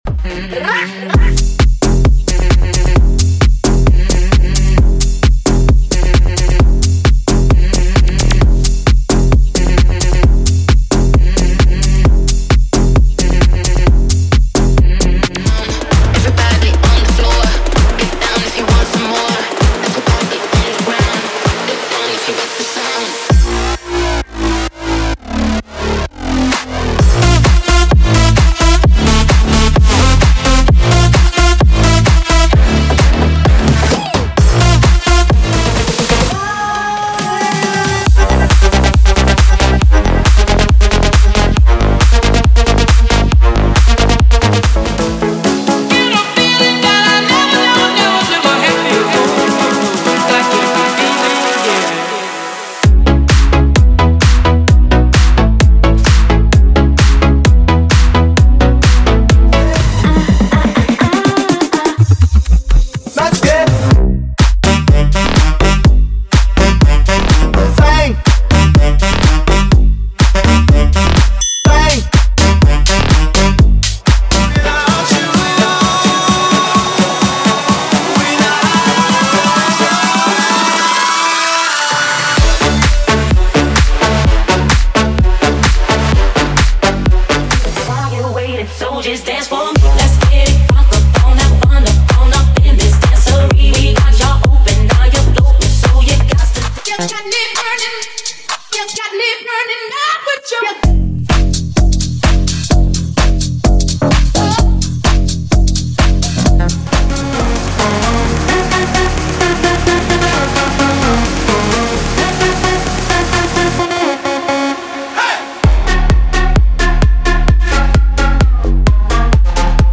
Sem Vinhetas
Em Alta Qualidade